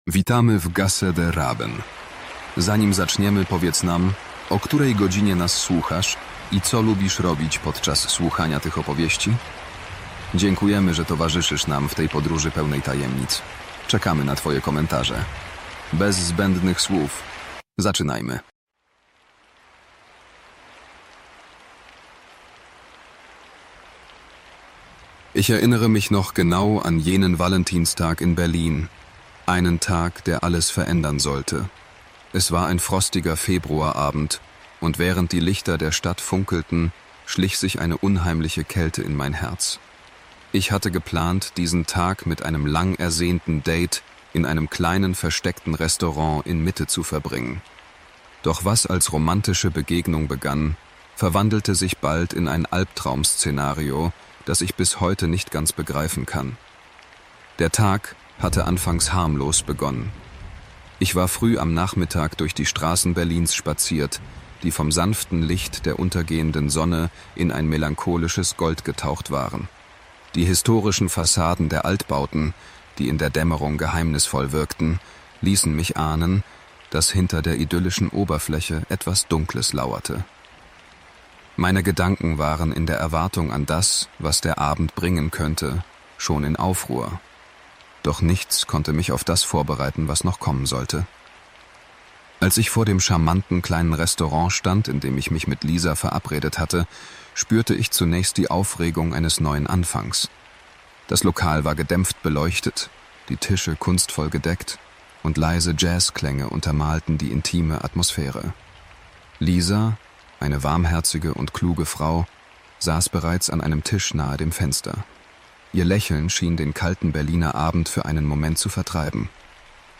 Einschlafen mit Angst: 1 Stunde echte Gruselgeschichten bei Regengeräuschen